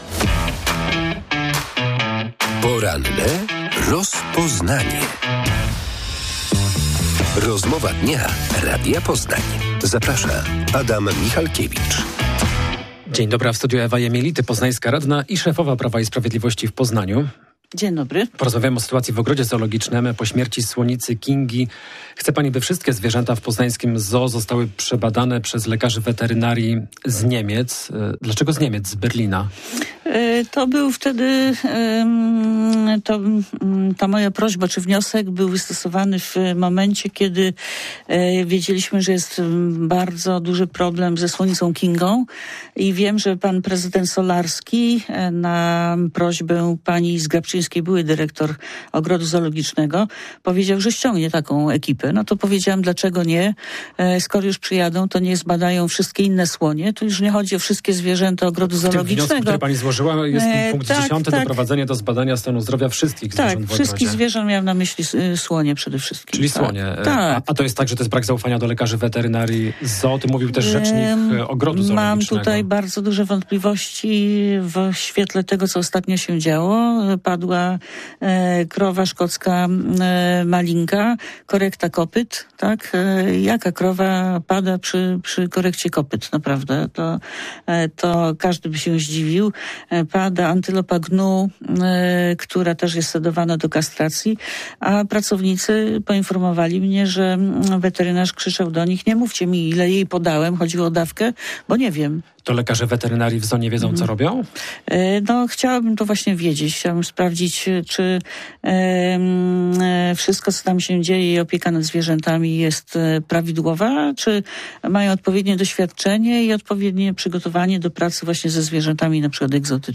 Poznańscy radni przyjadą do zoo, by sprawdzić sytuację w ogrodzie zoologicznym. Poinformowała o tym w porannej rozmowie Radia Poznań Ewa Jemielity z Prawa i Sprawiedliwości (PiS).